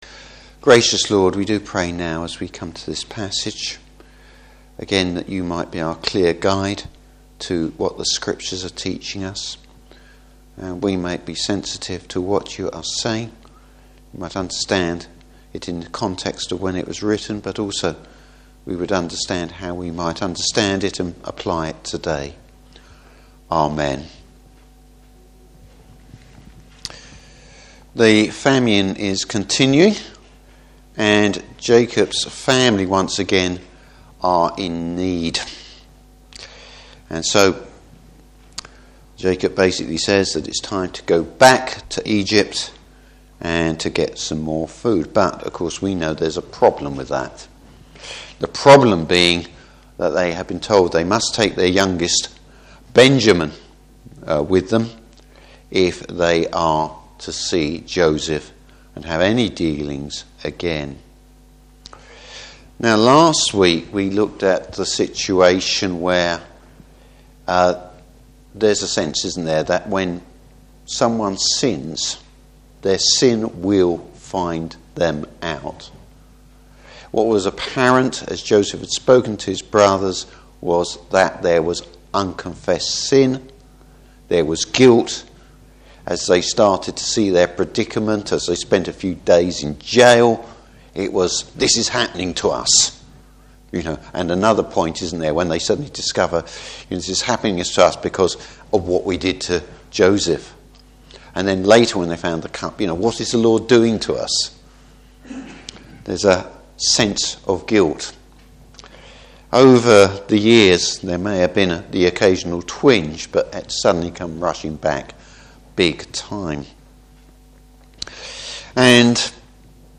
Service Type: Evening Service Joseph’s brother’s false sense of security.